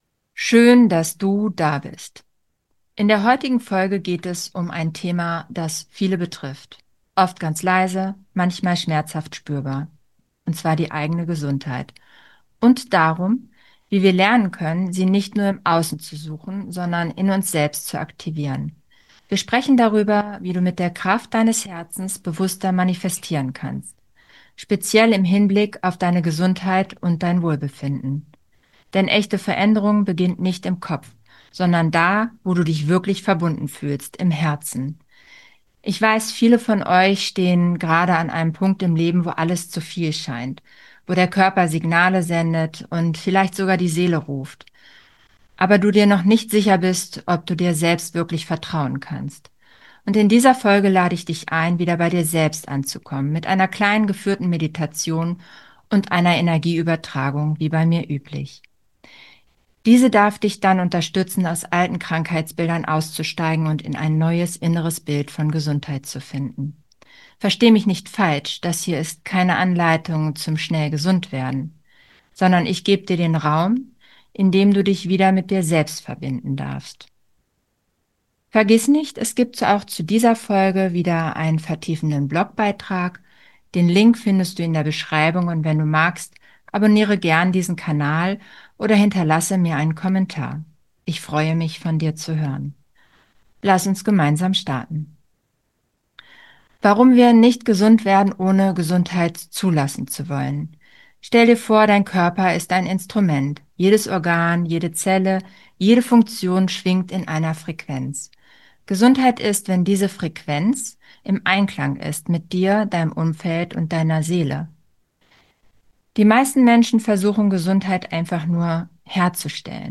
Mit einer geführten Meditation und energetischer Heilarbeit unterstütze ich dich dabei, destruktive Muster loszulassen, dich mit deiner inneren Wahrheit zu verbinden und neue gesunde Impulse zu manifestieren.